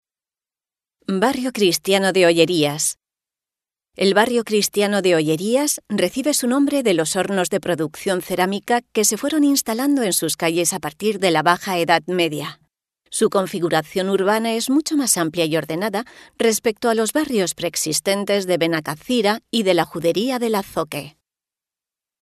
Audioguía Barrios Históricos: